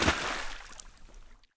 shallow-water-07.ogg